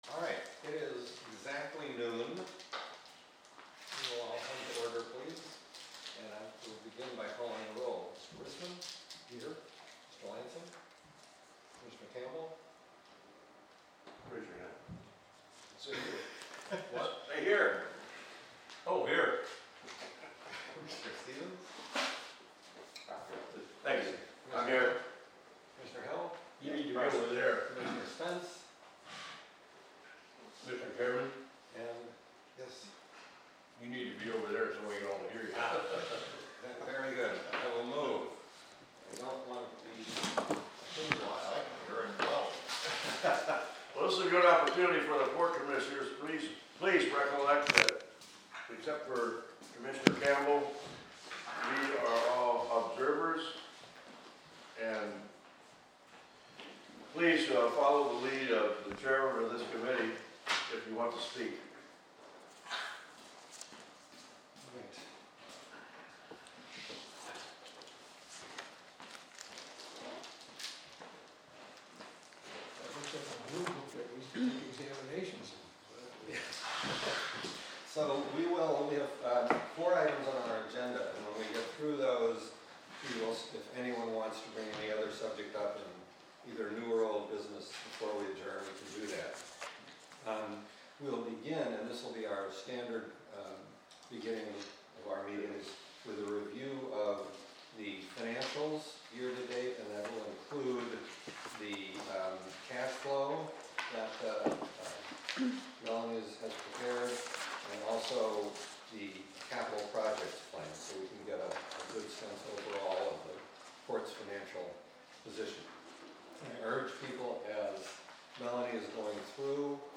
Finance Committee Meeting
422 Gateway Avenue Suite 100, Astoria, OR, at 12 PM